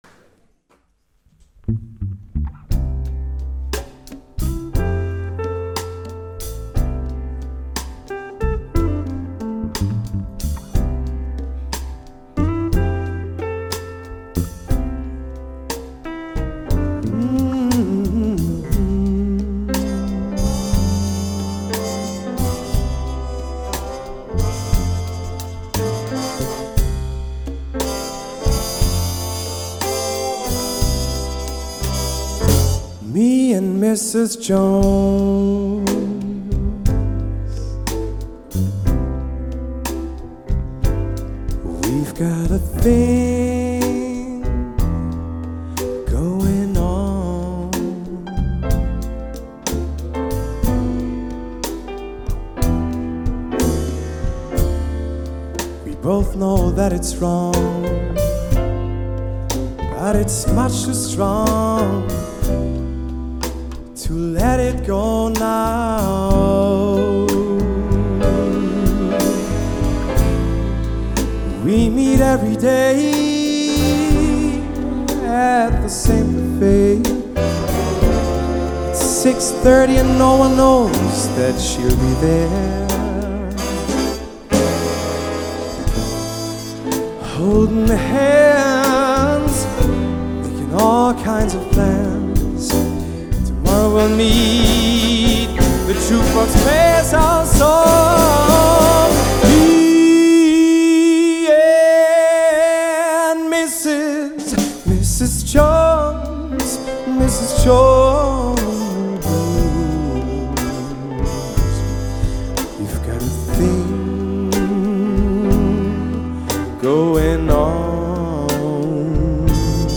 25 Musiker – ein Sound.
Jazz, Soul oder Pop: Die Bandbreite ist groß.